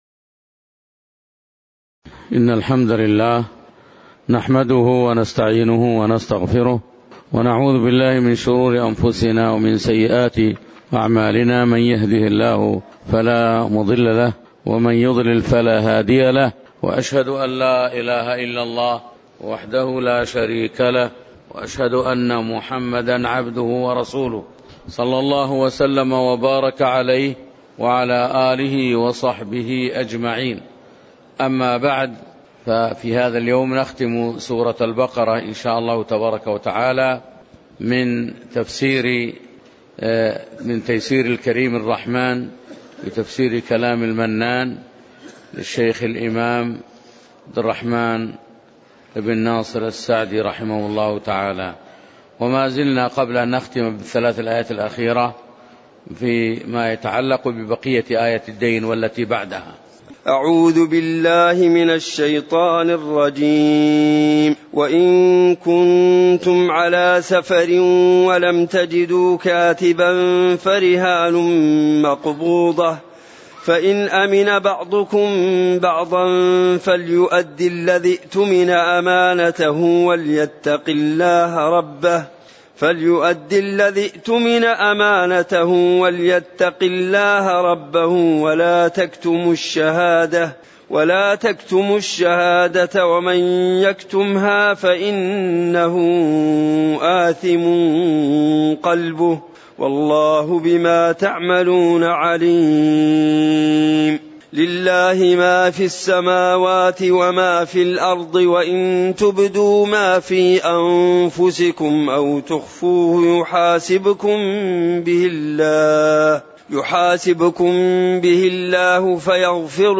تاريخ النشر ٢٧ شعبان ١٤٣٩ هـ المكان: المسجد النبوي الشيخ